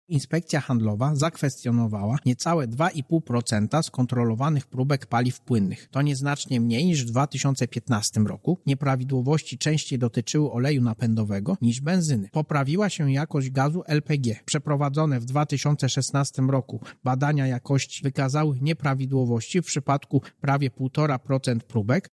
– Kontrolowane stacje zostały wybrane losowo. – mówi Marek Niechciał, prezes Urzędu Ochrony Konkurencji i Konsumentów.